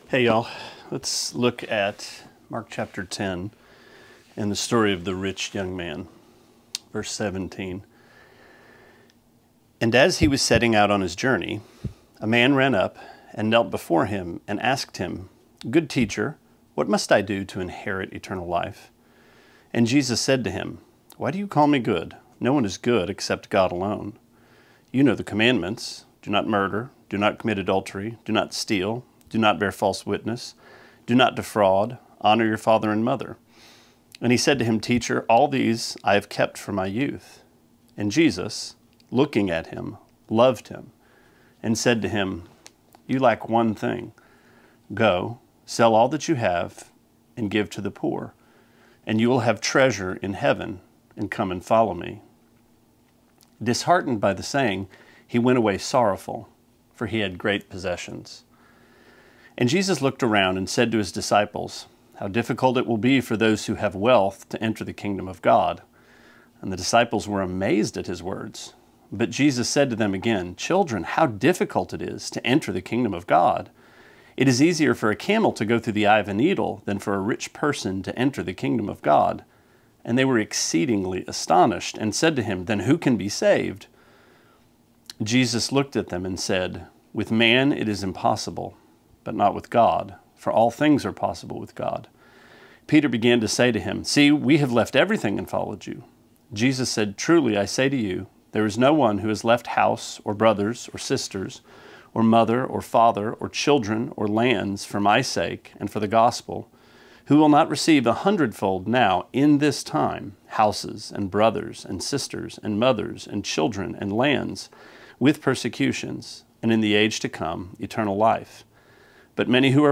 Sermonette 7/24: Mark 10:17-31: Love Hurts